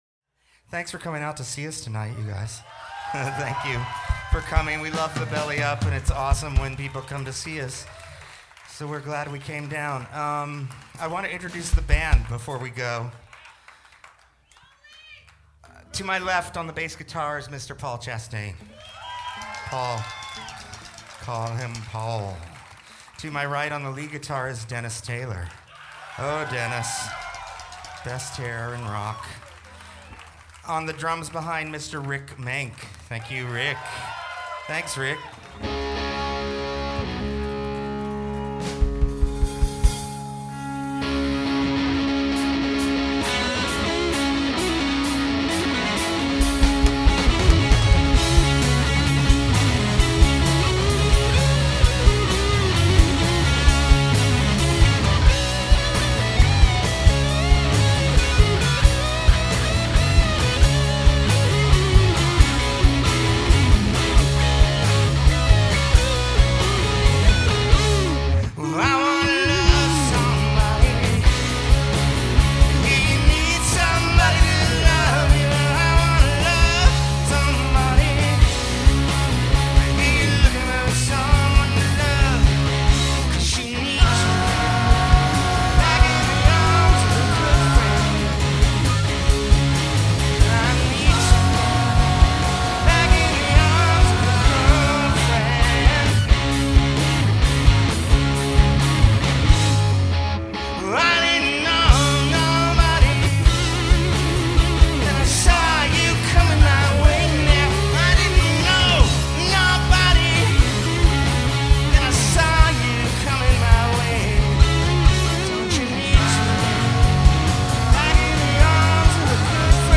the crowd waited patiently for his showstopper
with this solid performance.